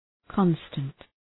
Shkrimi fonetik {‘kɒnstənt}